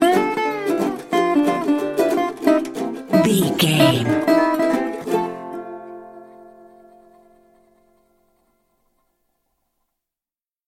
Ionian/Major
acoustic guitar
ukulele
slack key guitar